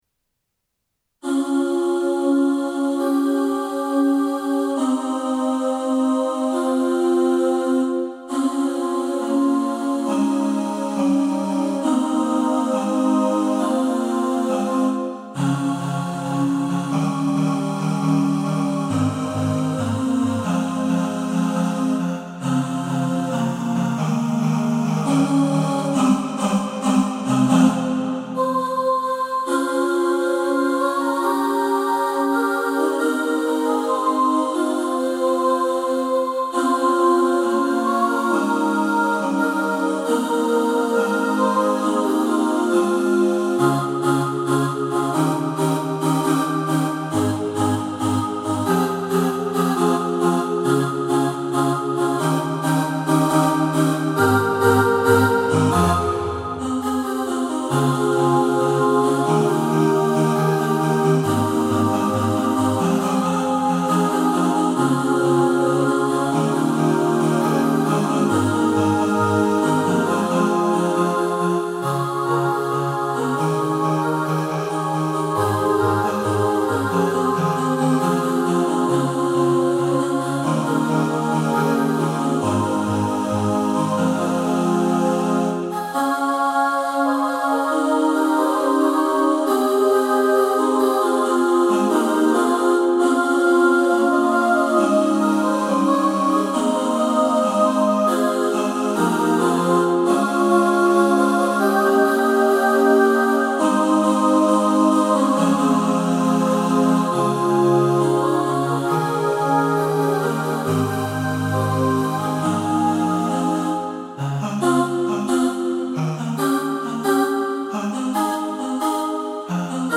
Viva-La-Vida-V2-All-Voices | Ipswich Hospital Community Choir